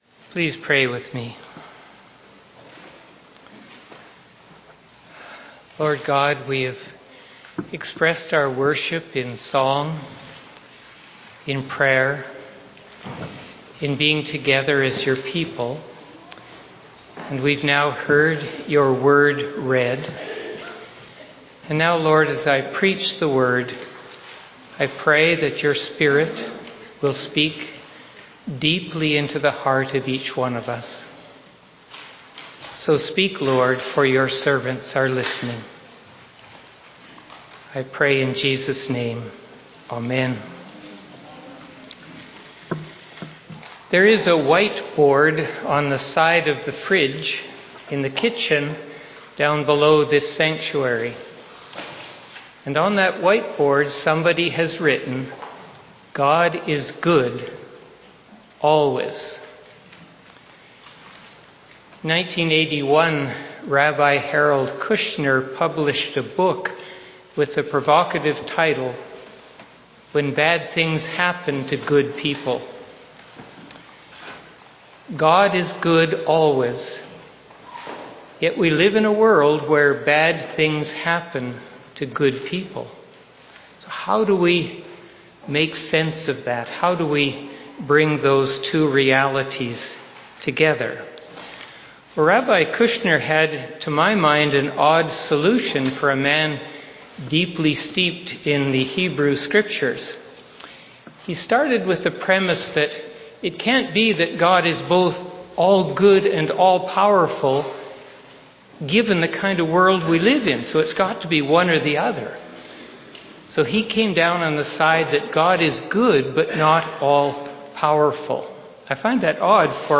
2025 Sermon November 16 2025